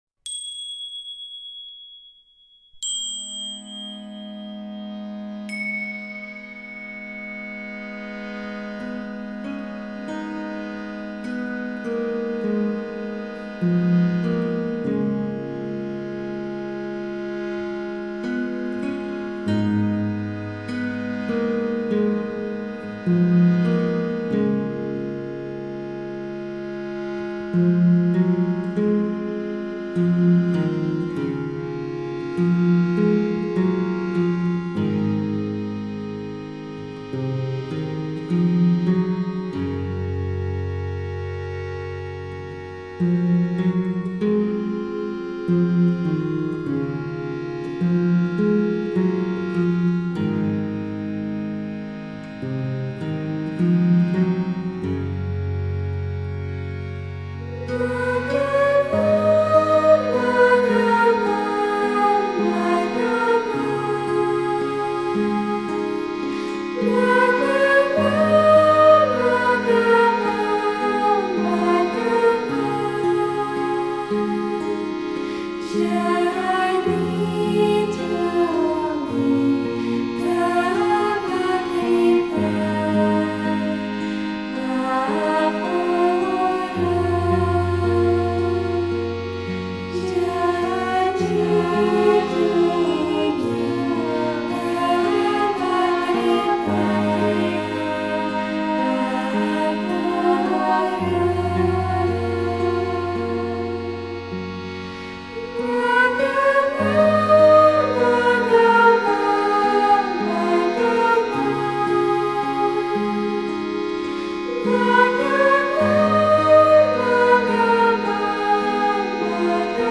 devotional and meditative arrangement